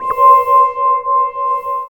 13 SCI FI -R.wav